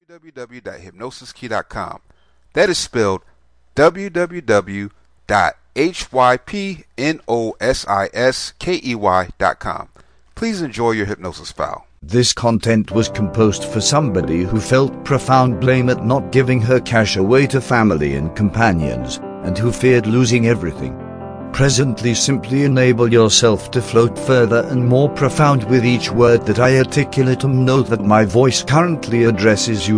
Welcome to Sensible Spending Self Hypnosis Mp3, this is a powerful hypnosis script that helps you develop sensible spending habits. self hypnosis.